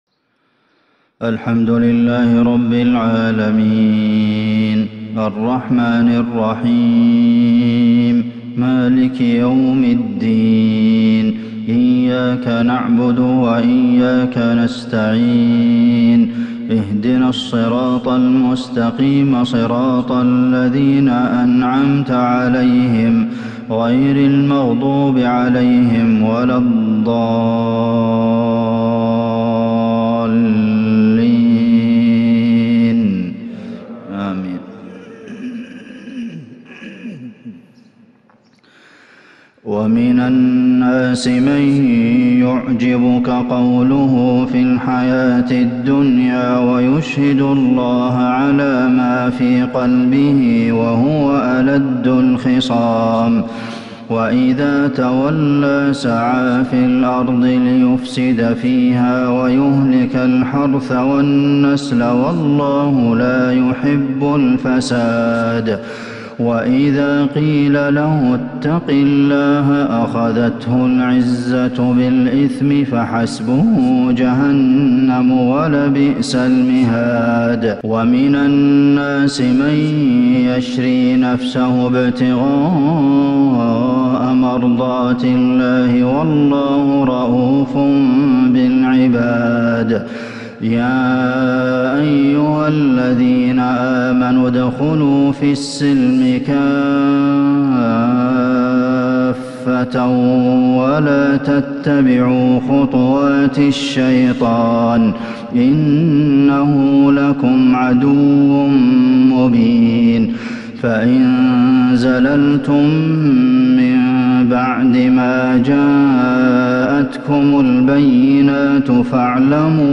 فجر السبت 8-7-1442هـ من سورة البقرة | Fajr prayer from Surah Al-Baqara 20/2/2021 > 1442 🕌 > الفروض - تلاوات الحرمين